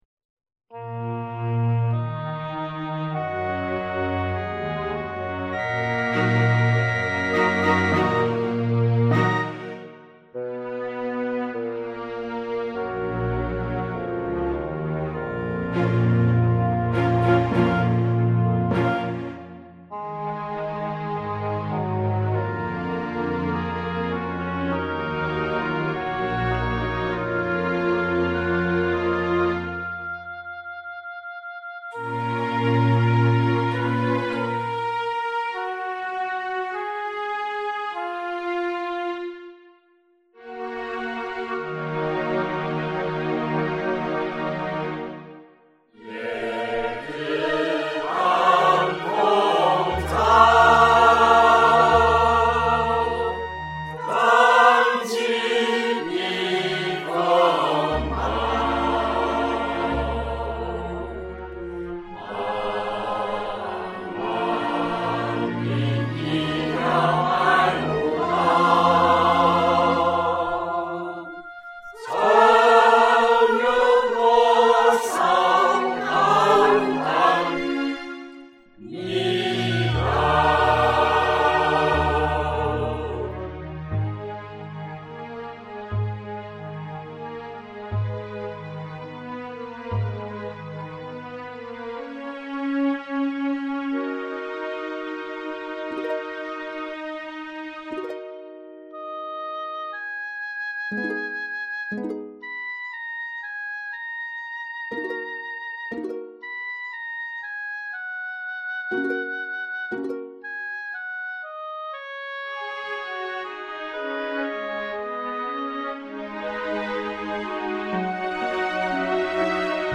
就如歌中所唱的, 白雾道虽然有风险, 只要牢记先辈的教诲和过道的口诀,蔼也能平安过去的, 这歌从歌纸看已经是三年前写的, 过后就忙于搞演出, 荒废了创作, 如今已是农闲时期, 吃包组在吞下一粒包之后就把合唱部分给唱出来了, 独唱就以后再说了, 如果高山有歌手要帮忙唱我就省下不少买包的钱可以买零件组装多几台wireless-midi.
吃包组伴唱: